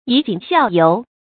以儆效尤 yǐ jǐng xiào yóu 成语解释 儆：告诫；警戒；效尤：效仿过错；学人家做坏事。
成语繁体 以儆俲尤 成语简拼 yjxy 成语注音 ㄧˇ ㄐㄧㄥˇ ㄒㄧㄠˋ ㄧㄡˊ 常用程度 常用成语 感情色彩 中性成语 成语用法 动宾式；作谓语；同杀一儆百 成语结构 动宾式成语 产生年代 近代成语 成语正音 儆，不能读作“jìnɡ”。